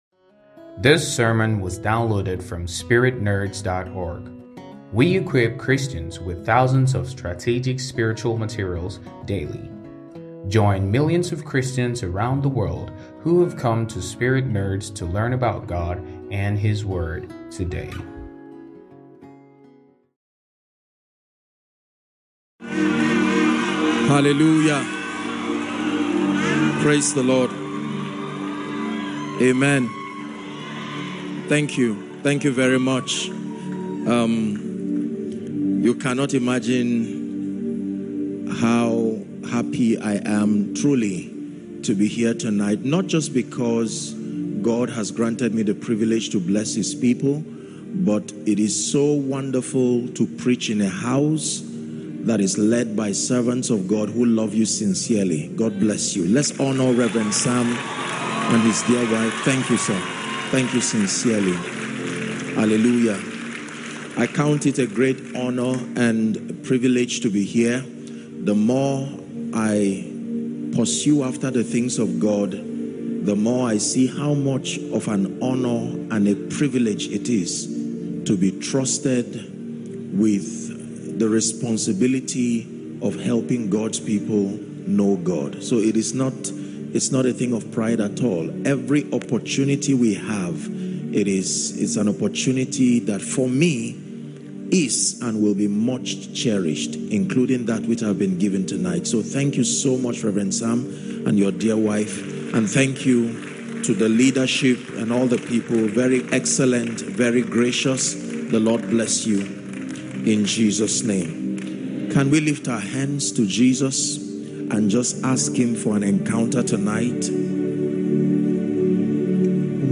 GILGAL 2023 , TTC ABUJA